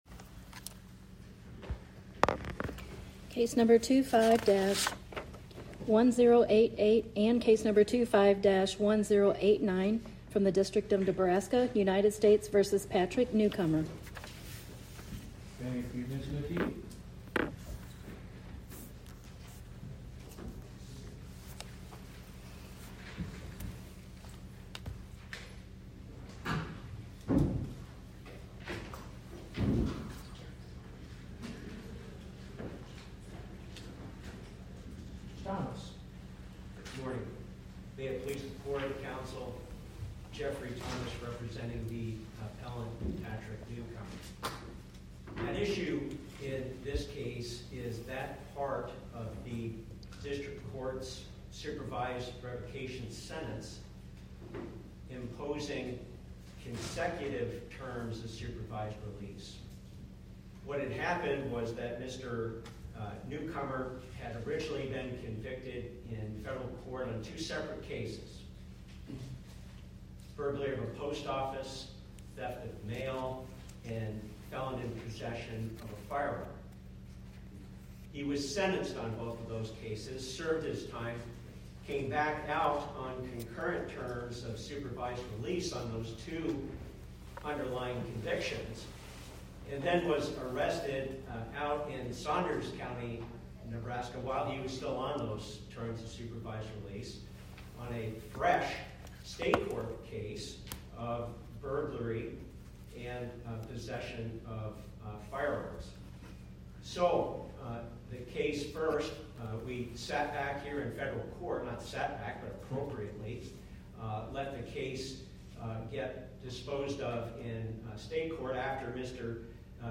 Oral argument argued before the Eighth Circuit U.S. Court of Appeals on or about 11/18/2025